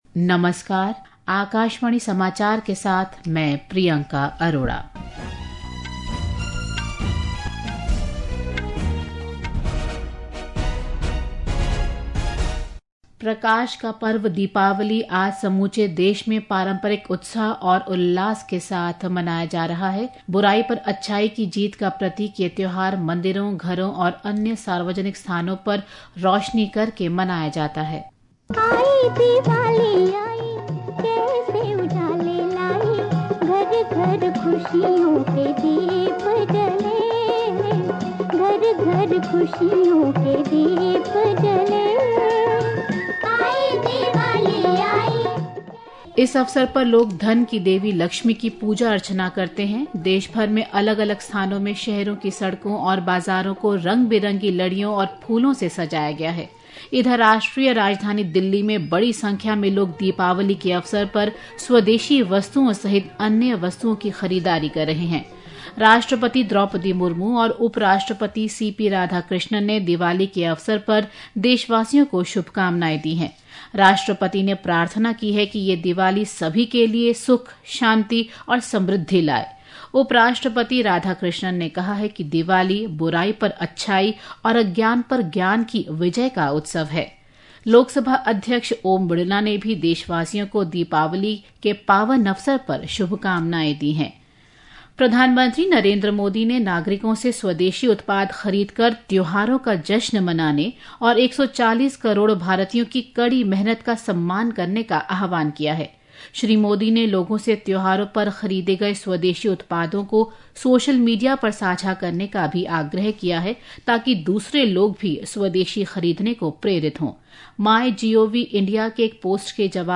राष्ट्रीय बुलेटिन
प्रति घंटा समाचार